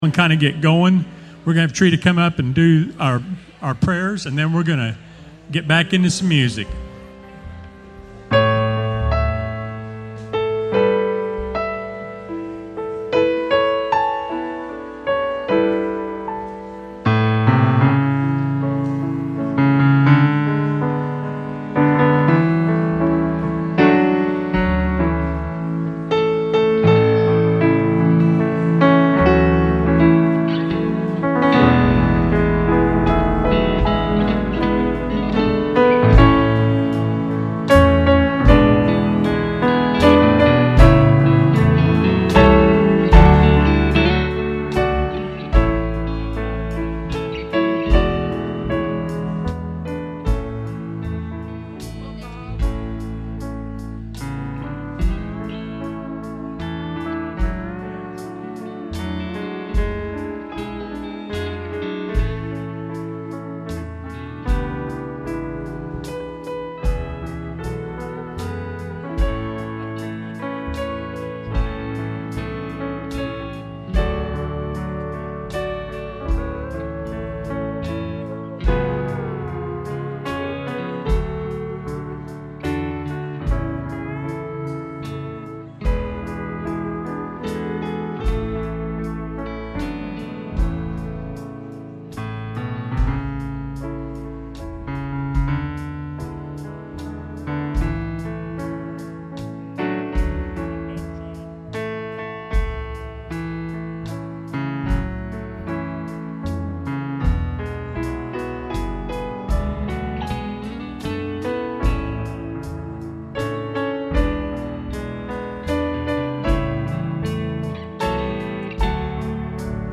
Dedication of “The Barn” worship center